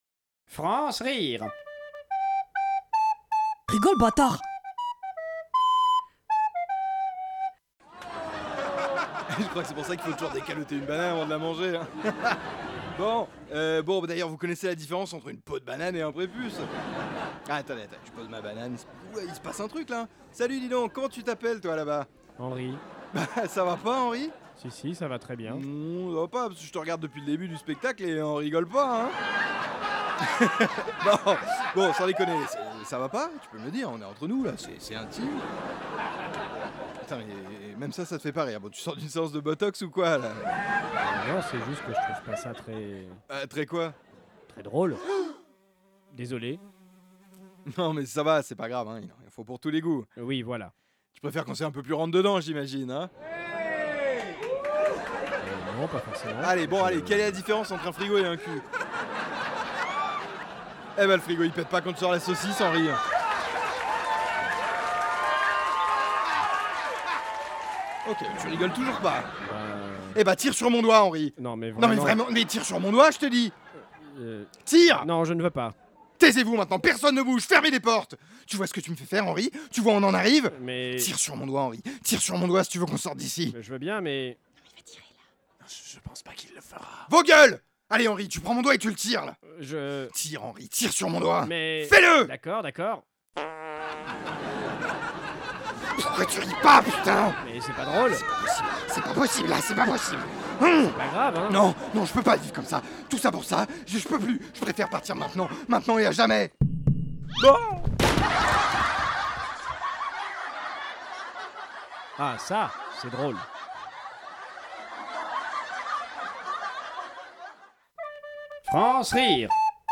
Sketch drôle
Création sonore
Sketch drôle avec une chute.